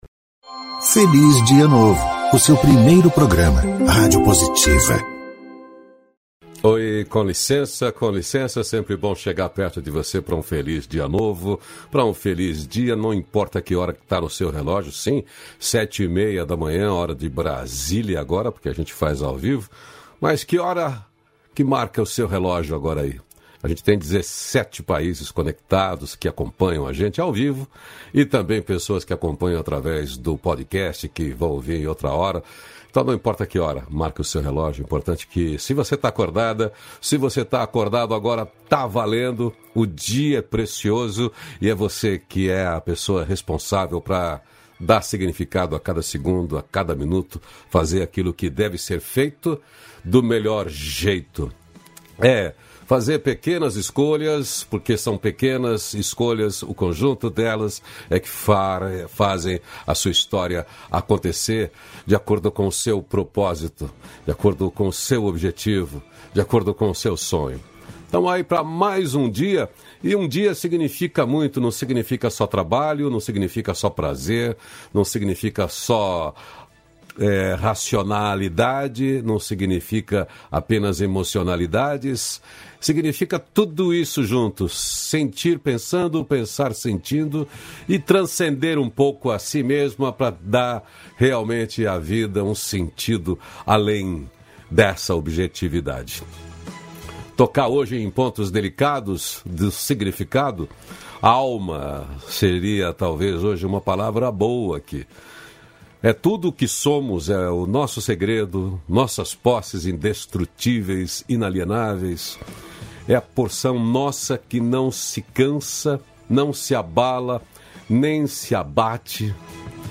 326-feliz-dia-novo-entrevista.mp3